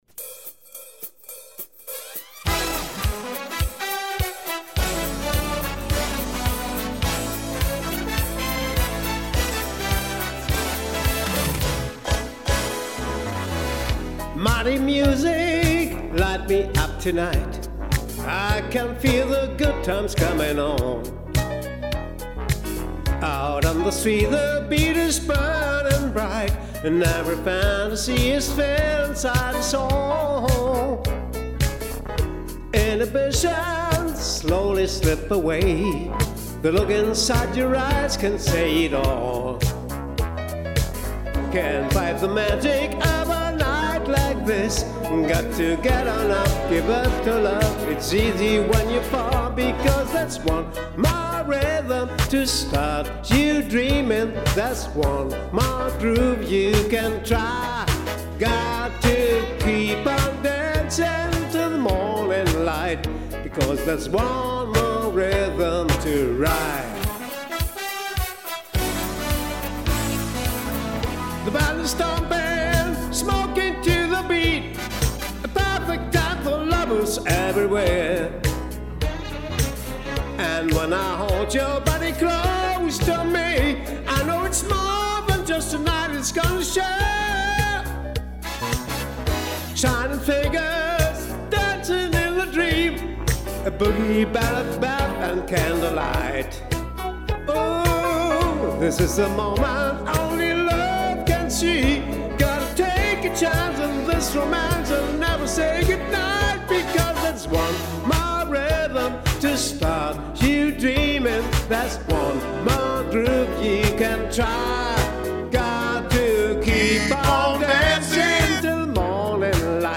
Style: Soul / RnB
Microphone: RODE NT1-A